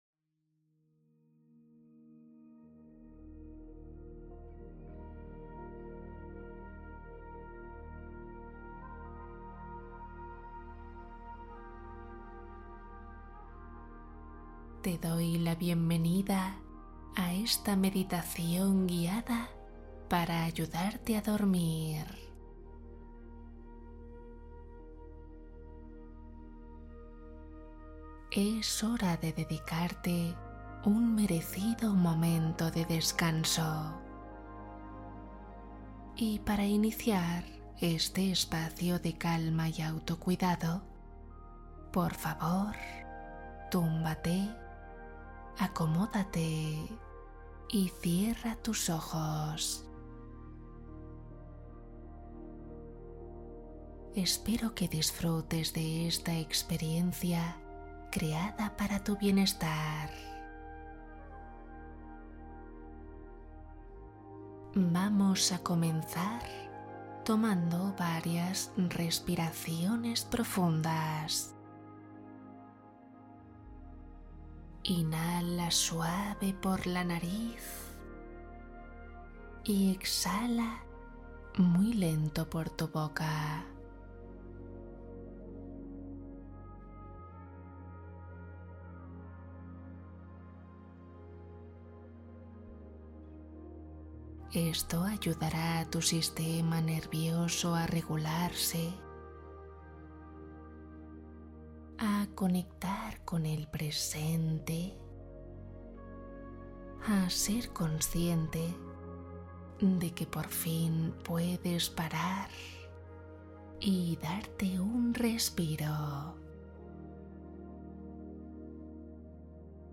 Alivia insomnio y ansiedad Meditación guiada para un sueño reparador